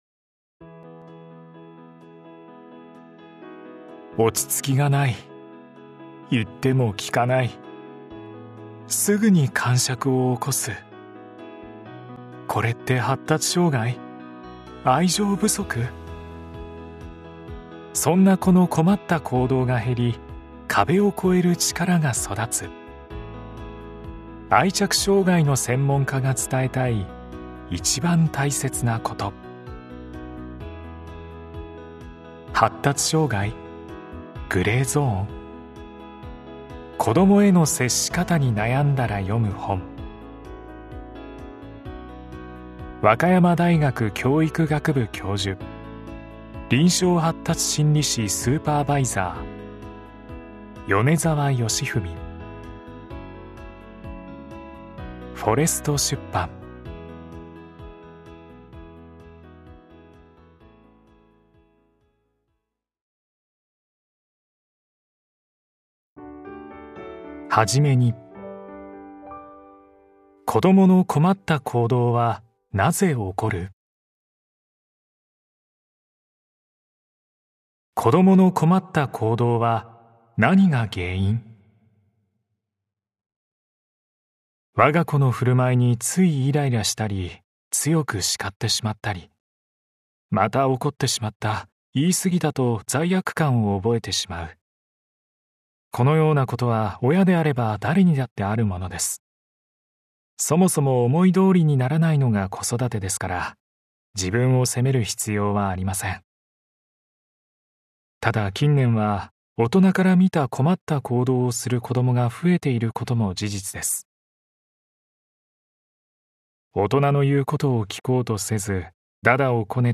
[オーディオブック] 発達障害？グレーゾーン？こどもへの接し方に悩んだら読む本